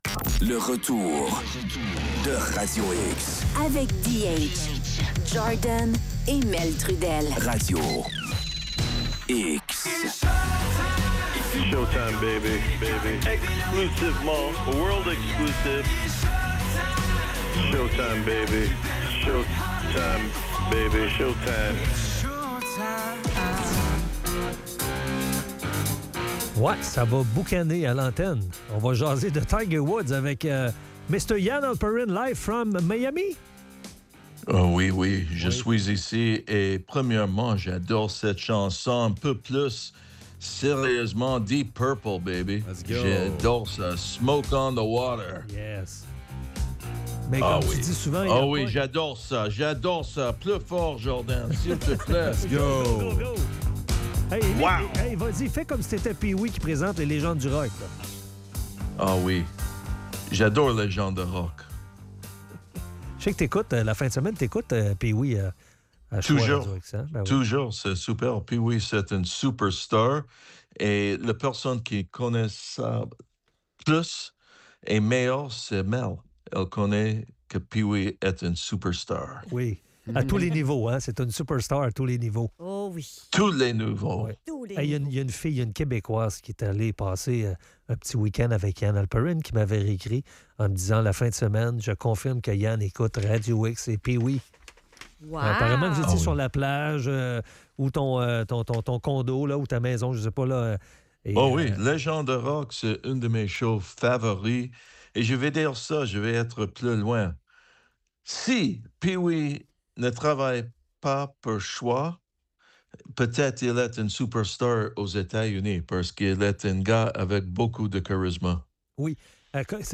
La chronique de Ian Halperin.